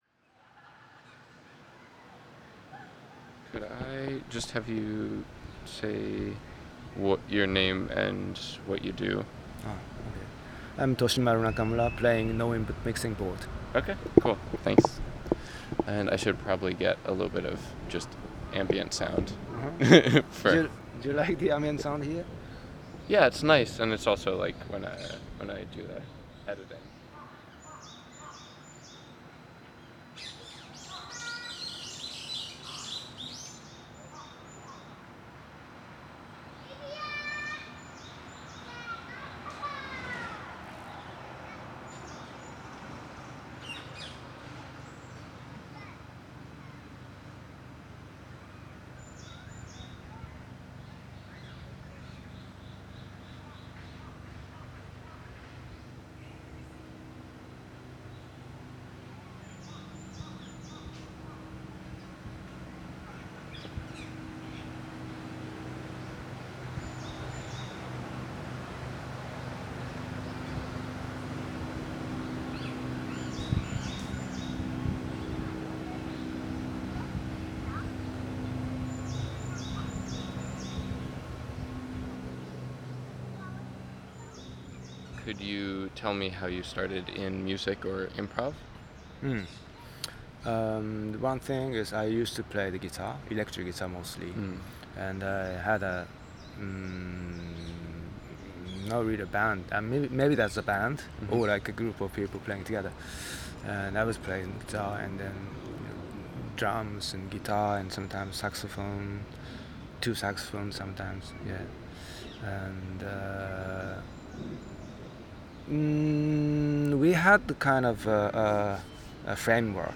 and few interviews with noise artists associated with the infamous OFFSITE venue.